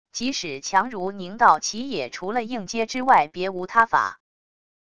即使强如宁道奇也除了硬接之外别无他法wav音频生成系统WAV Audio Player